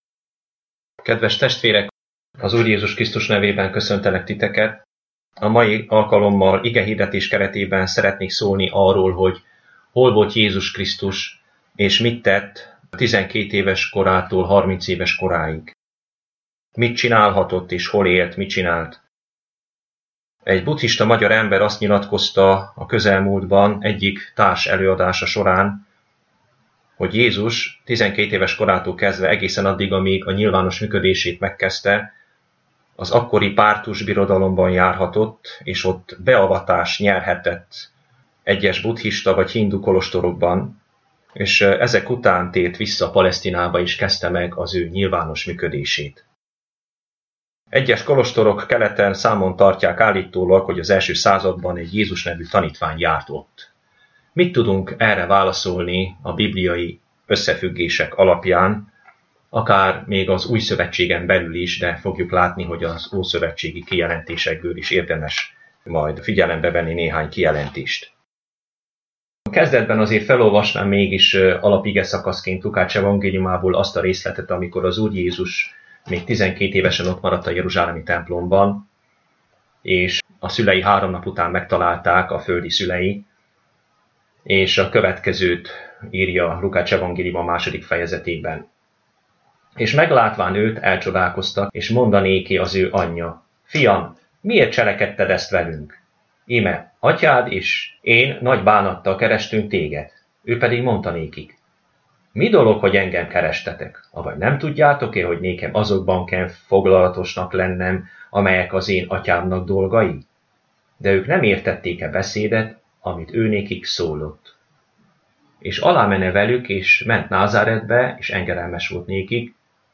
Igehirdetések mp3 Az igehirdetés meghallgatásához kattints ide!